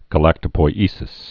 (gə-lăktə-poi-ēsĭs)